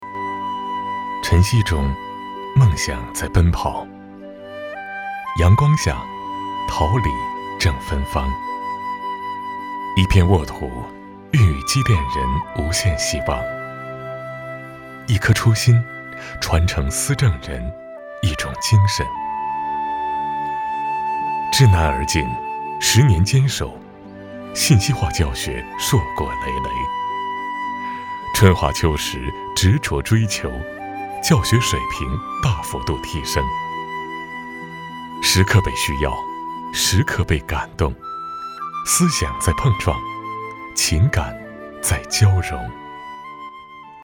深情缓慢 学校专题
大气稳健，浑厚低沉，深沉底蕴男音，擅长讲述、宣传片不同题材配音，作品：厦门锐建 ，机电学校。